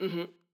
VO_ALL_Interjection_14.ogg